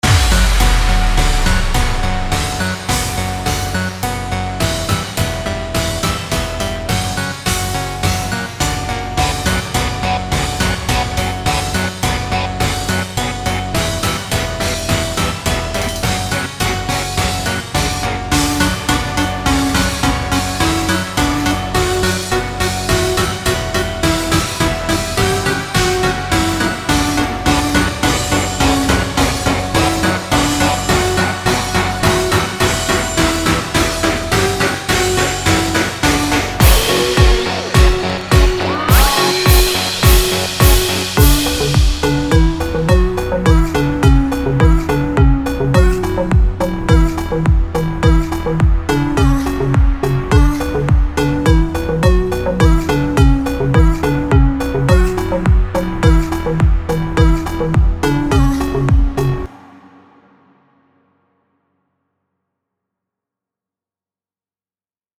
Сегодня начал сочинять. Под репчик.